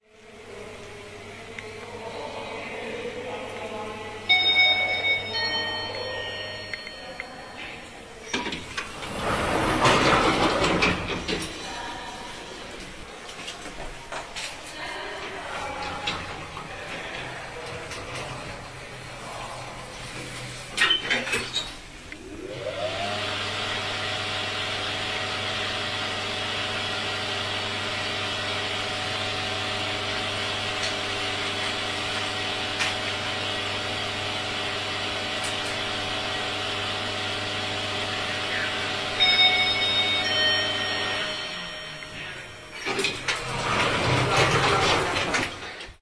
电梯 旧公寓楼
描述：这是一个老式公寓楼里的旧电梯，有滑动的笼门。
标签： 升降机 公寓大楼 拨浪鼓
声道立体声